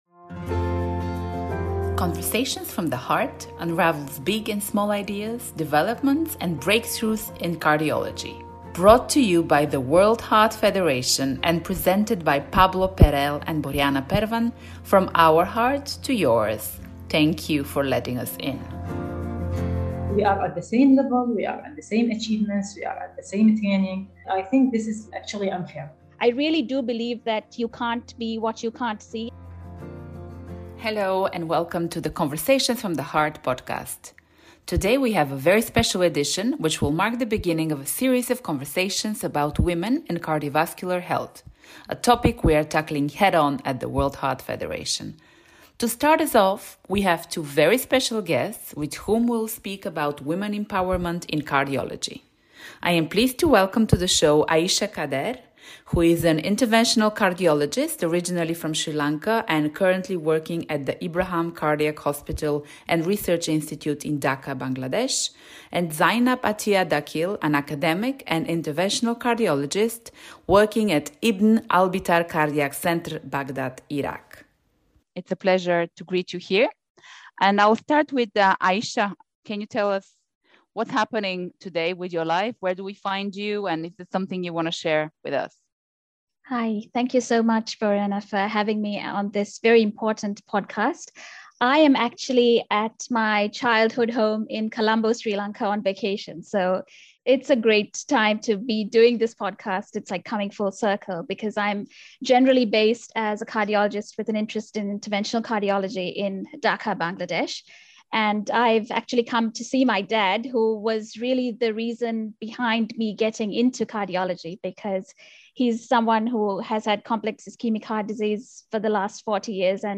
This conversation is the first in a series focusing on women in cardiovascular health , exploring their journeys, challenges, and the future of female leadership in cardiology.